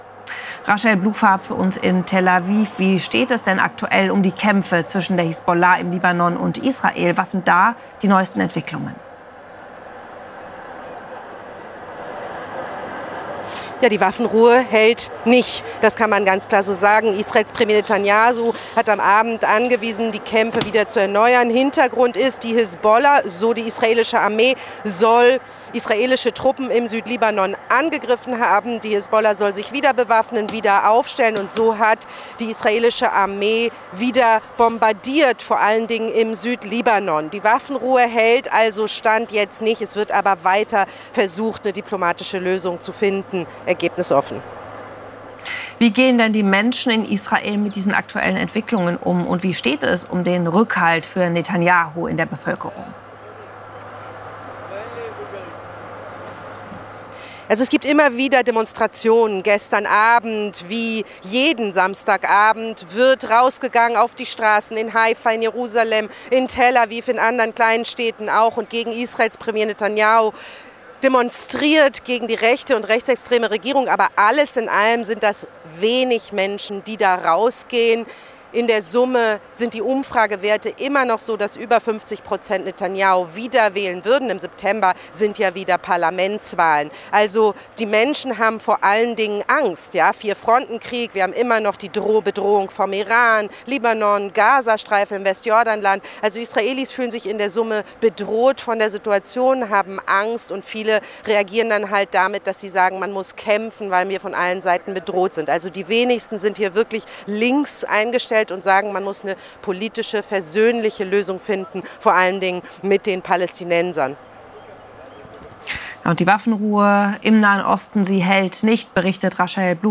berichtet aus Tel Aviv über die Stimmung im Land.